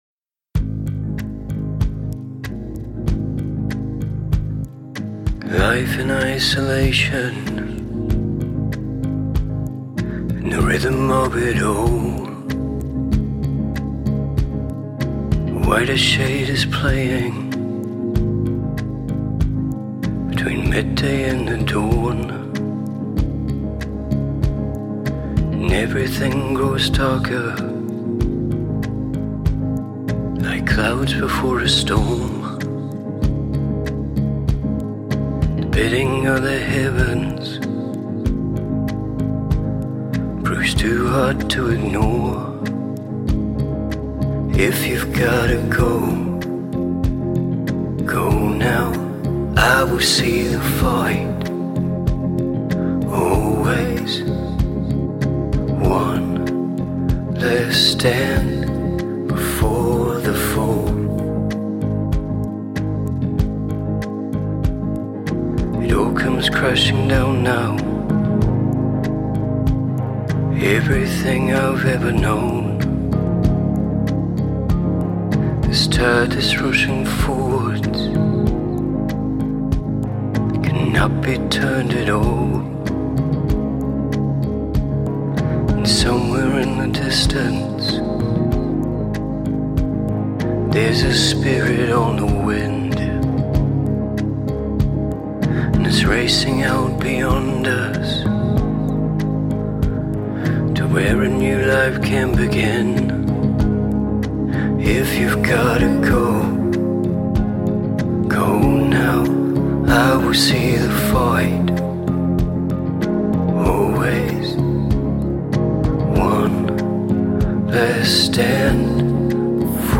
I did some heavy-handed EQ and sent the original to a delay and reverb both with the output of those shelved up at one end and down at the other, more or less (very quick).